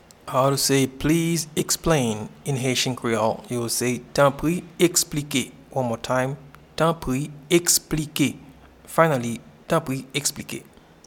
Pronunciation and Transcript:
Please-explain-in-Haitian-Creole-Tanpri-eksplike.mp3